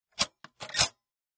3 /4" Video tape deck tape loads and plays. Tape Loading Transport Engage